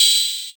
Gamer World Open Hat 7.wav